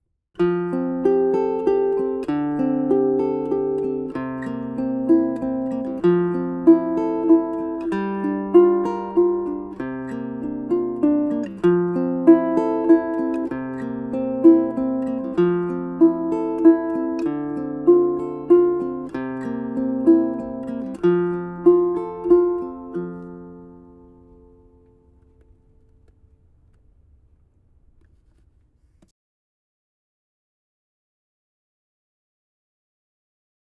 Your left-hand holds the chords as if strumming, but your right-hand plucks the tones one note at a time in a repeating pattern.
P-i-m-a-m-i Arpeggio | First line of Cuckoo (guilele)
The rhythm of P-i-m-a-m-i is a flow of even eighth notes: 1 & 2 & 3 &.
Cuckoo_Uke_pimami.mp3